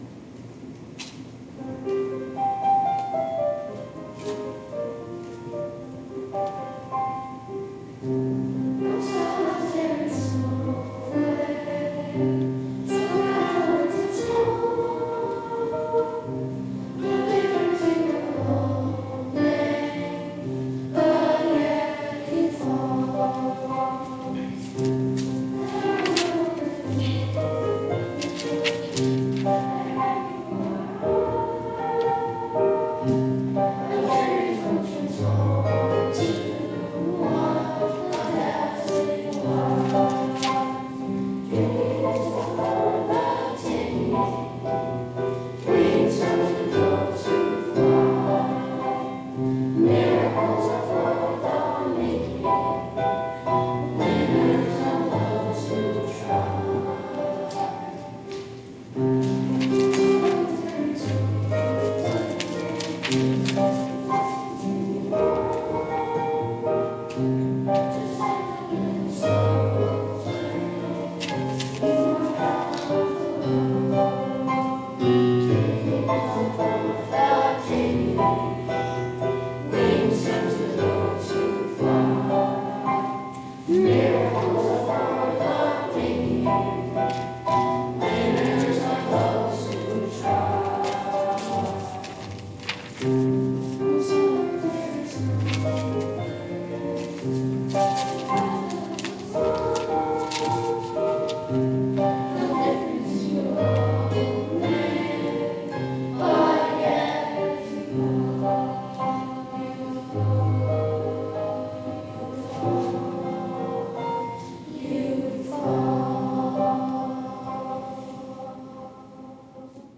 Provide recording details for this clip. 2020 TBA Holiday Concert Preview !!!